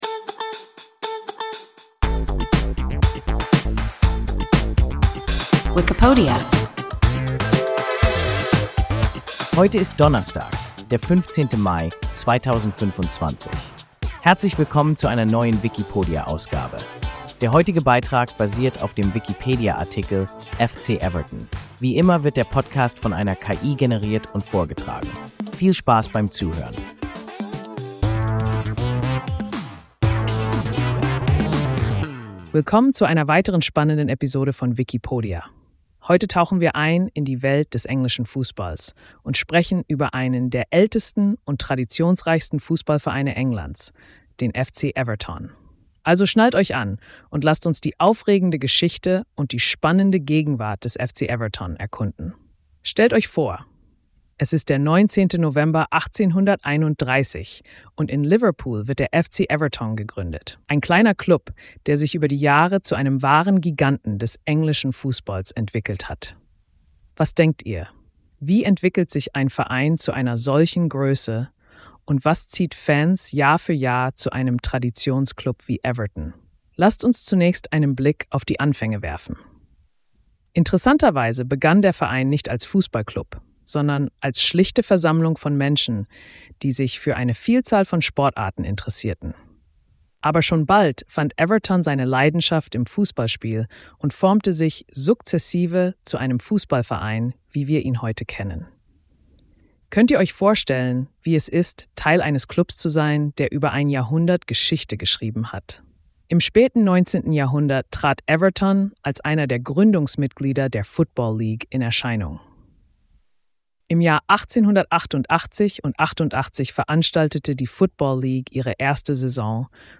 FC Everton – WIKIPODIA – ein KI Podcast